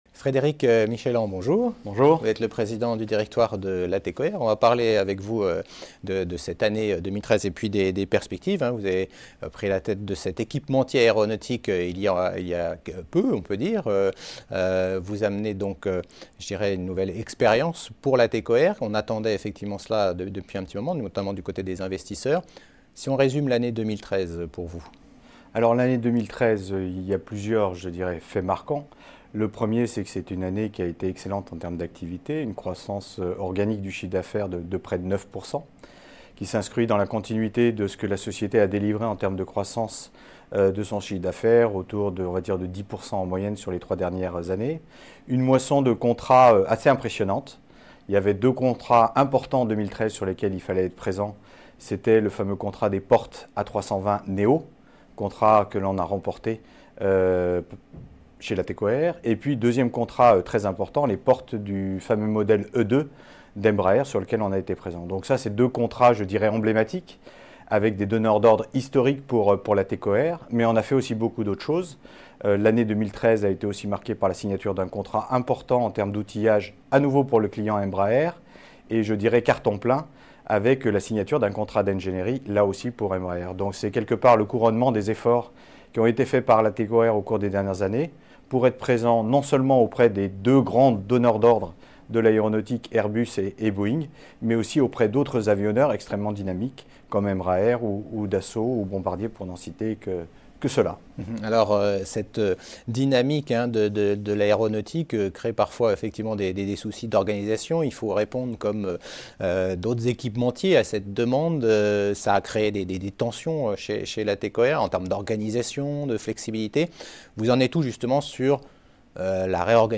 Category: L'INTERVIEW